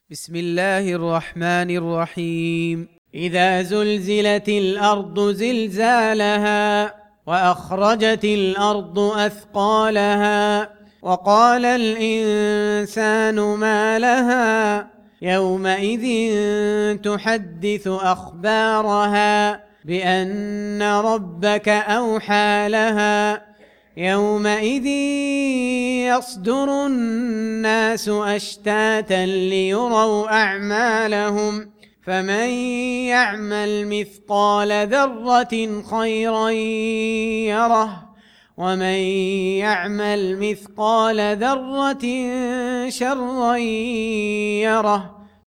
99. Surah Az-Zalzalah سورة الزلزلة Audio Quran Tarteel Recitation
Surah Sequence تتابع السورة Download Surah حمّل السورة Reciting Murattalah Audio for 99. Surah Az-Zalzalah سورة الزلزلة N.B *Surah Includes Al-Basmalah Reciters Sequents تتابع التلاوات Reciters Repeats تكرار التلاوات